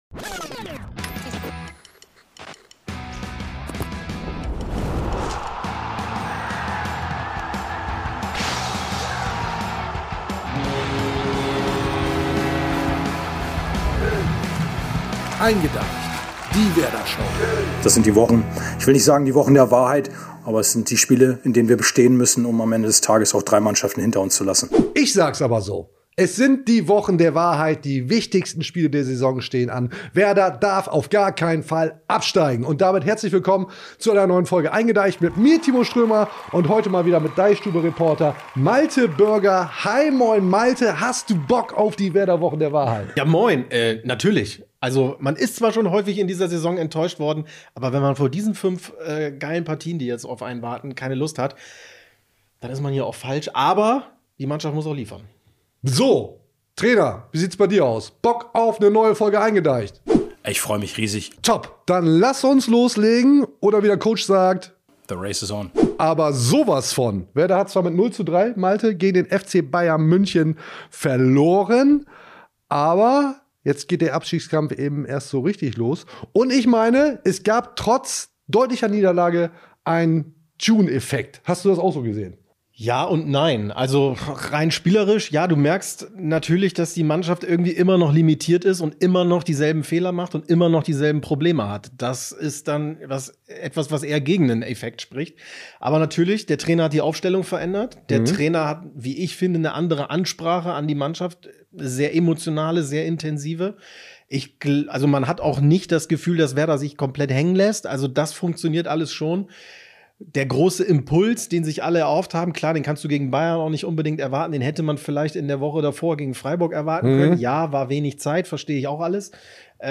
Denn in der Werder-Podcast-Show eingeDEICHt, gesendet aus dem DeichStube-Office, erwartet Euch wie immer eine Vollgas-Veranstaltung vollgestopft mit den Themen, die die Fans des SV Werder Bremen beschäftigen.
Viel zu viele Einspieler, allerlei Blödsinn, schlechte Wortwitze, dumme Sprüche, manchmal Werder-Expertise.